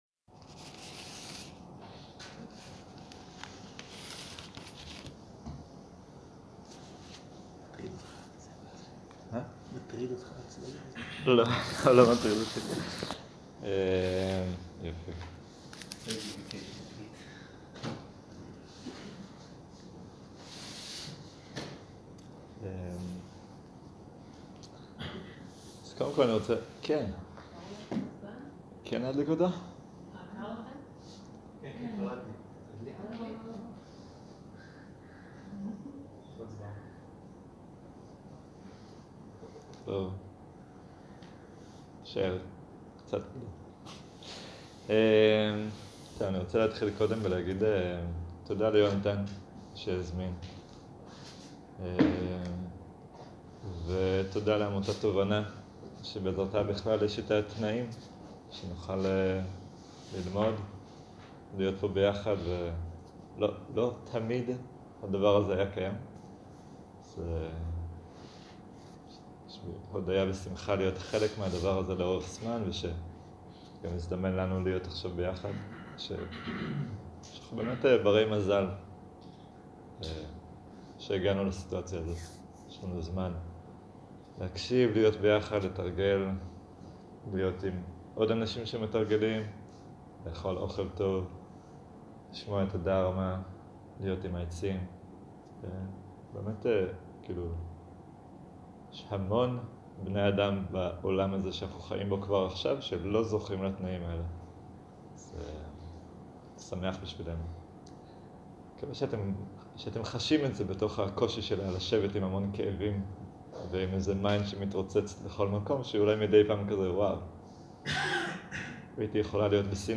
ערב - שיחת דהרמה - מה הקשר בין תשומת לב לנשימה והשחרור